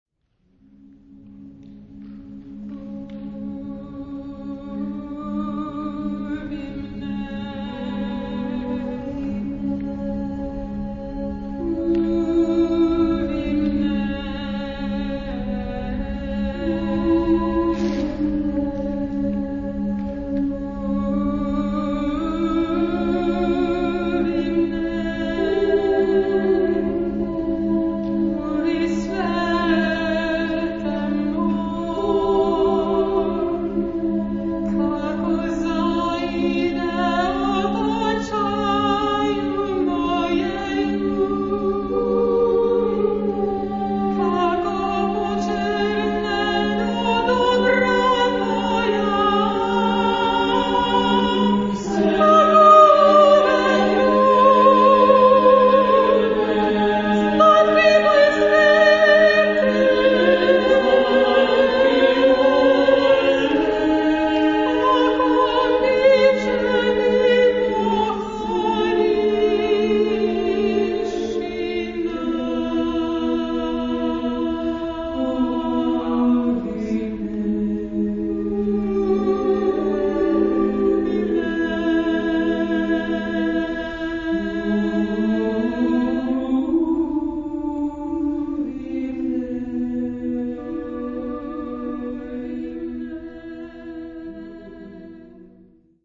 Genre-Stil-Form: weltlich ; Chor
Charakter des Stückes: tragisch ; geistlich
Chorgattung: SSAA  (4 Frauenchor Stimmen )
Tonart(en): modal